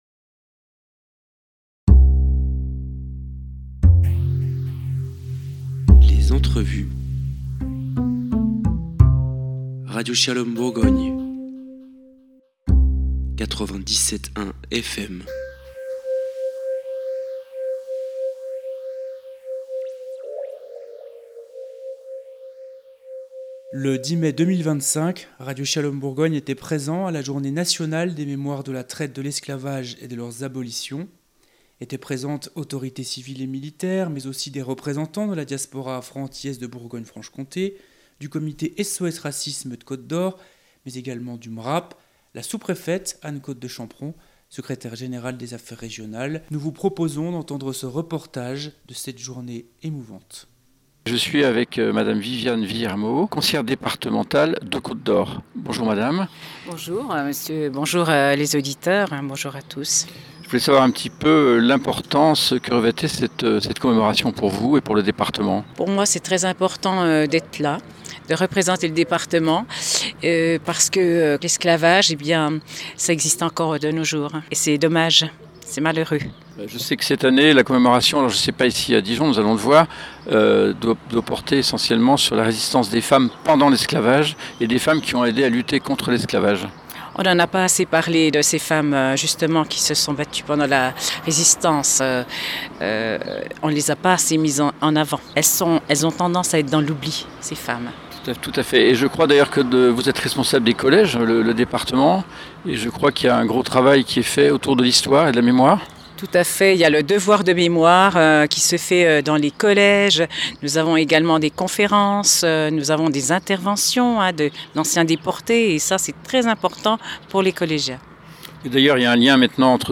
Le 10 mai 2025 radio shalom etait présent à la Journée nationale des mémoires de la traite, de l’esclavage et de leurs abolitions.
Madame la sous-préfète Anne-Coste de Champeron, secrétaire générale des affaires régionales, à donné une place importante aux femmes vicitimes de la traite & des femmes abolitionnistes. Nous vous proposons d'entendre plusieurs entrevues avec les représentants ainsi que des extraits de discours et de chants qui ont été réalisés durant cette cérémonie dont :